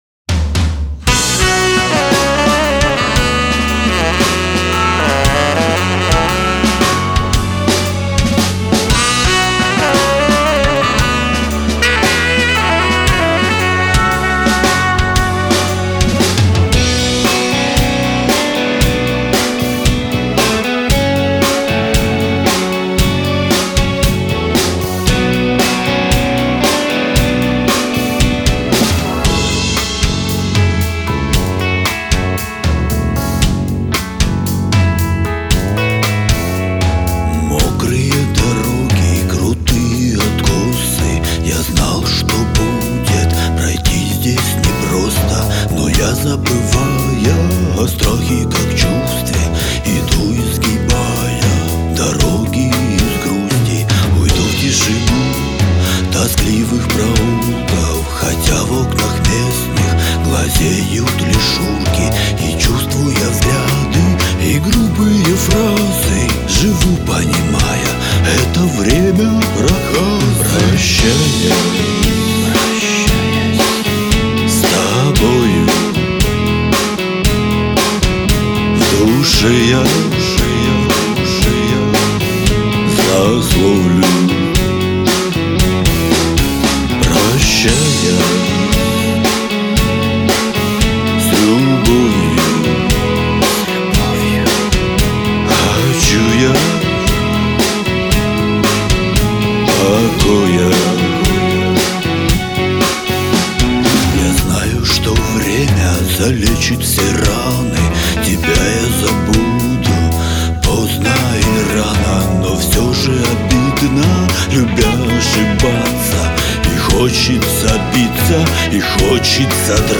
Стиль определен коллективом как клоун-поп-рок.